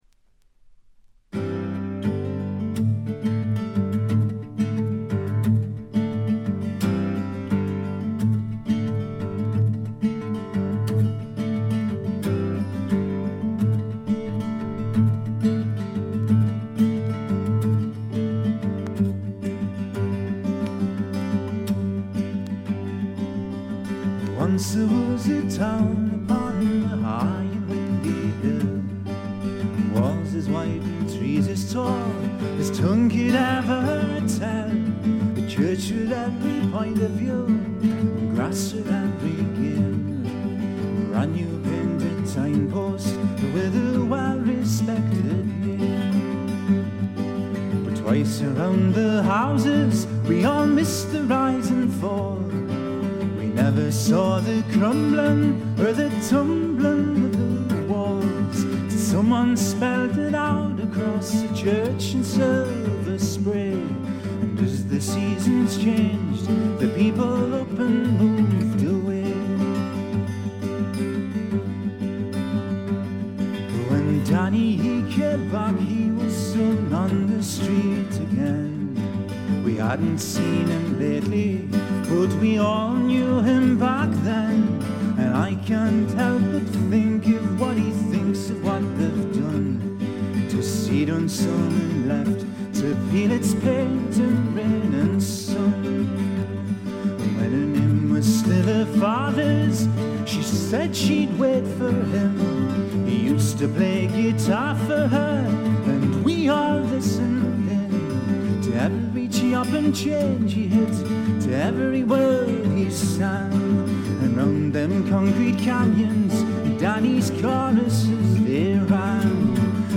ホーム > レコード：英国 フォーク / トラッド
部分試聴ですがチリプチ少々、散発的なプツ音少々。
試聴曲は現品からの取り込み音源です。